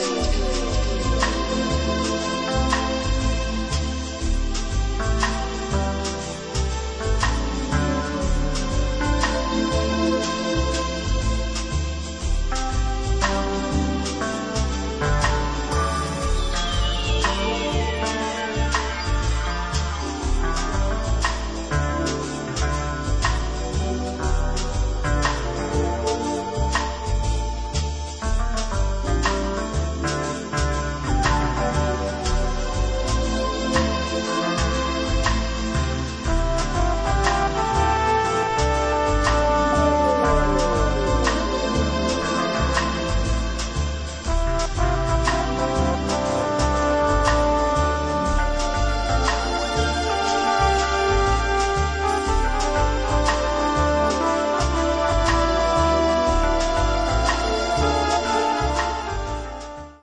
アンビエント/チルアウト・ルームの超絶クラシック・マスターピース！！